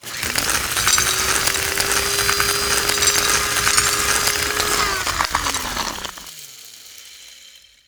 grindnothing.wav